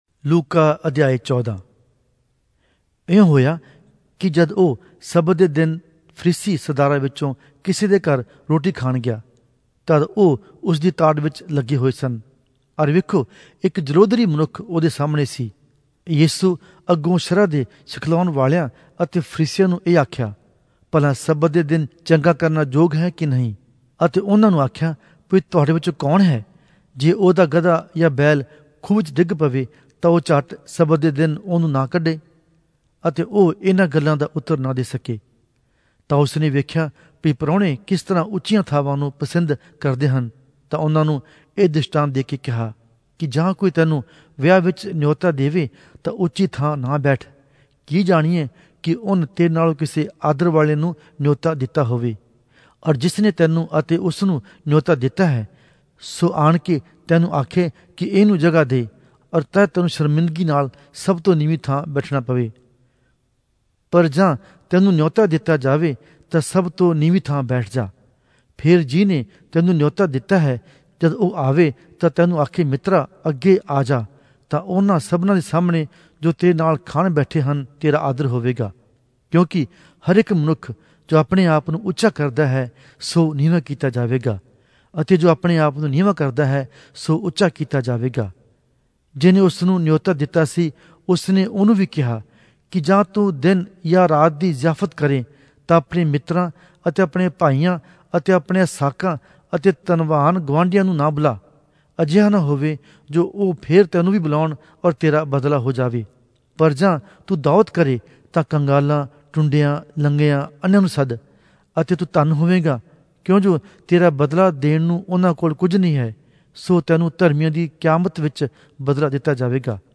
Punjabi Audio Bible - Luke 7 in Gntbrp bible version